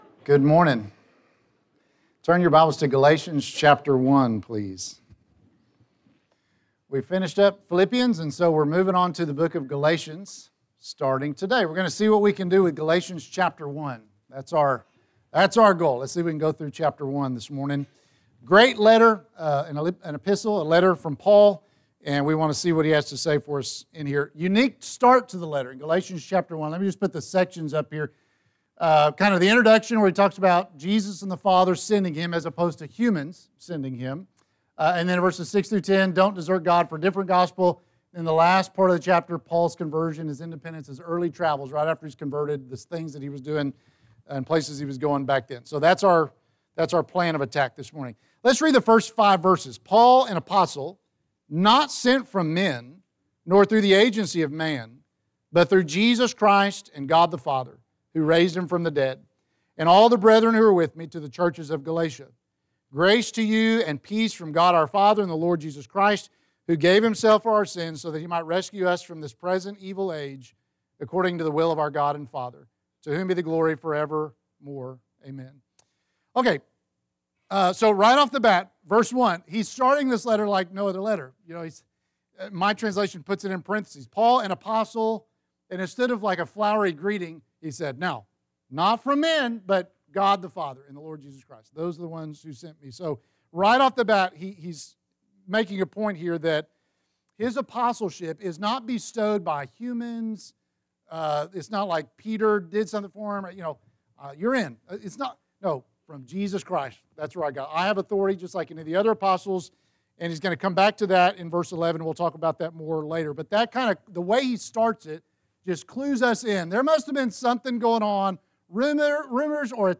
Classes - Benchley church of Christ